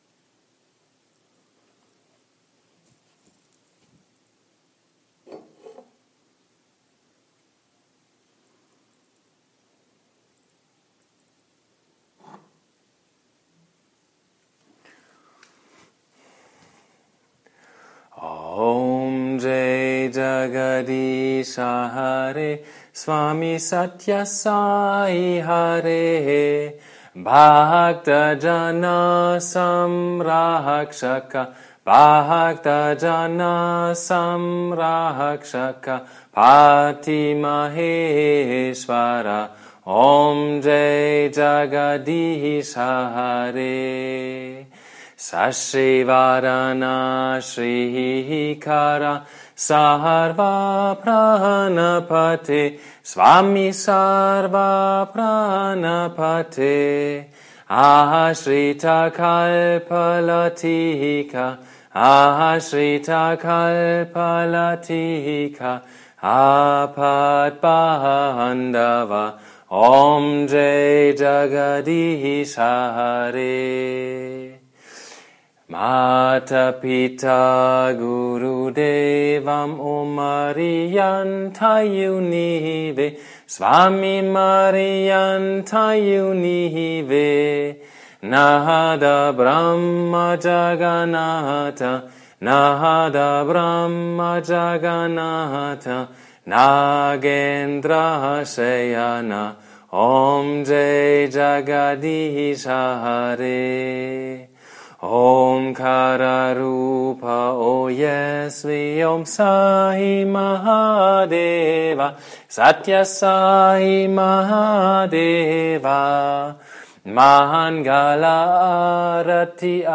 賽巴巴火典音檔